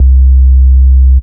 MB Sub (2).wav